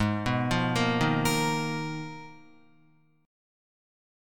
Abm6add9 chord